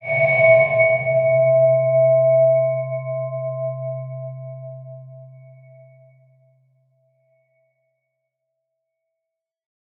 X_BasicBells-C1-pp.wav